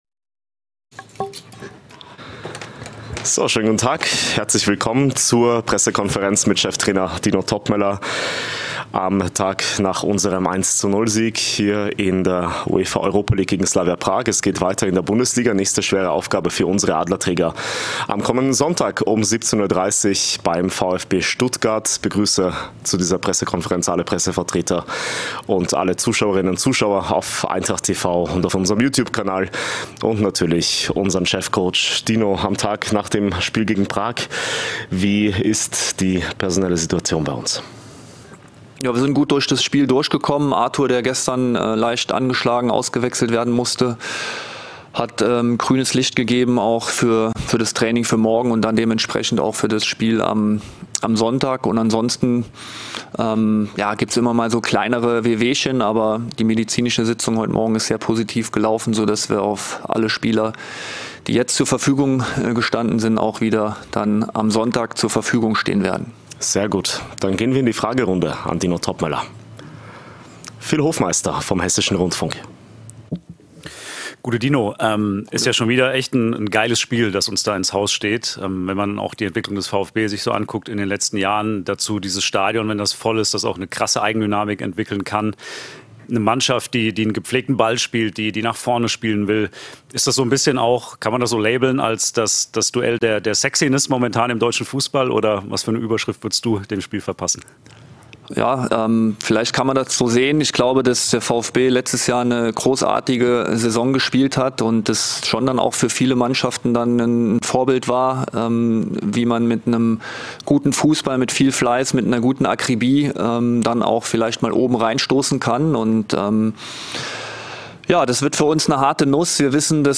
Die Pressekonferenz mit Cheftrainer Dino Toppmöller vor unserem Auswärtsspiel im Schwabenland.